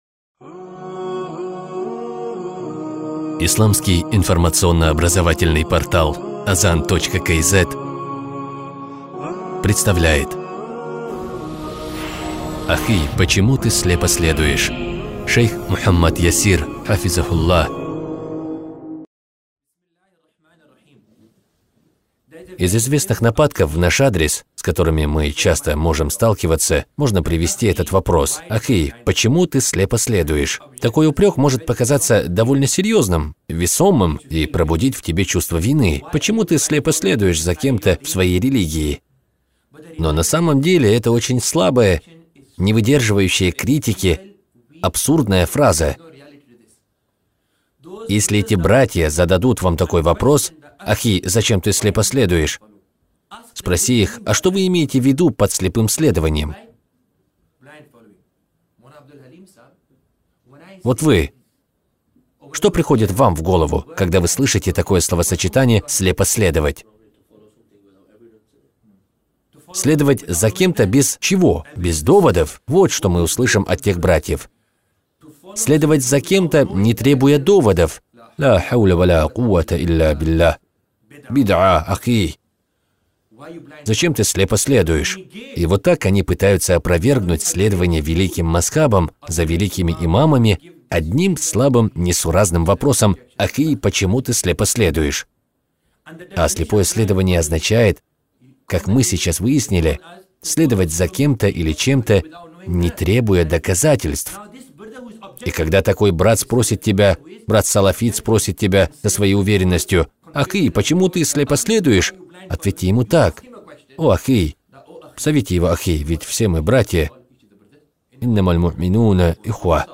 Лектор